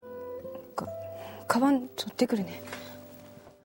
Kita akan mengangkat salah satu adegan dari dorama Nobuta o Produce episode 7 yang menggunakan -te kuru berkali-kali.